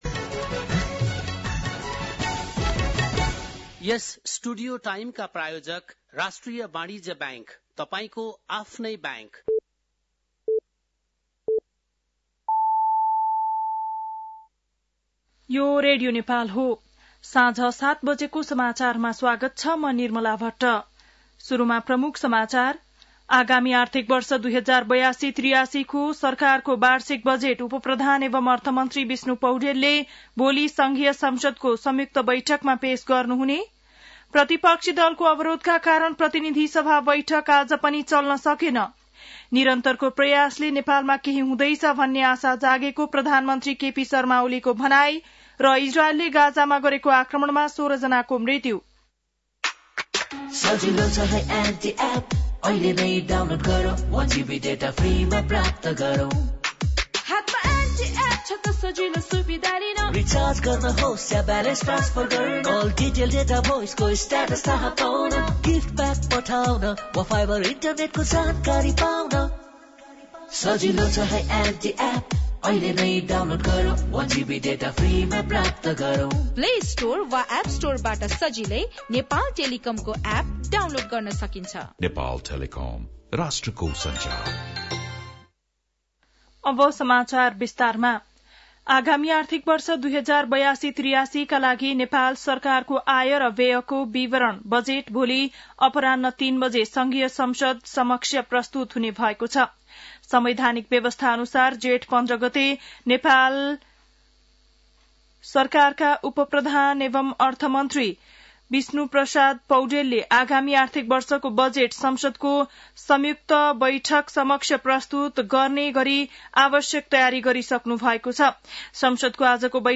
बेलुकी ७ बजेको नेपाली समाचार : १४ जेठ , २०८२